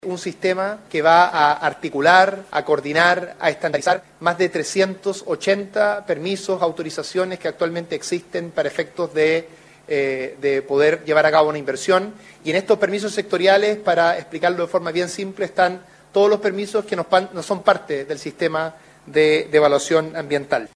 Por su parte, el ministro de Economía, Nicolás Grau, aseguró que en promedio los tiempos totales de tramitación sectorial se deberían reducir en más de un tercio, beneficiando sobre todo a empresas de menor tamaño.